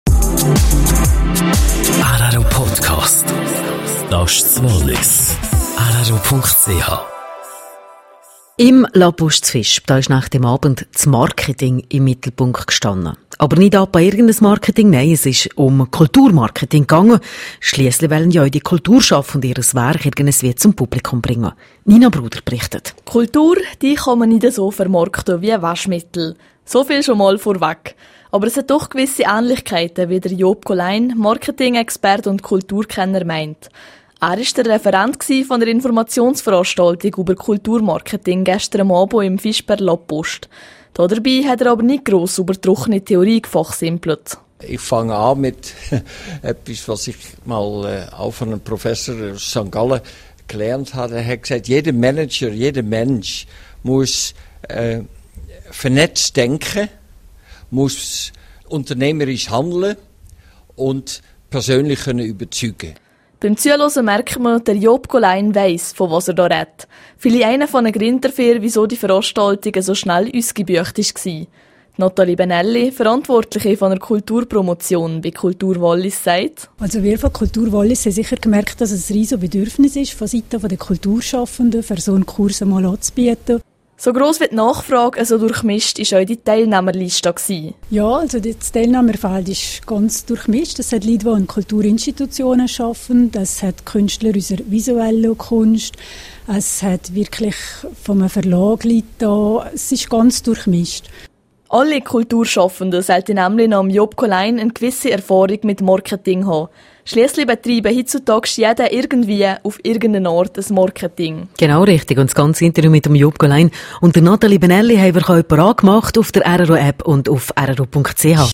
bn Informationsveranstaltung zu Kulturmarketing.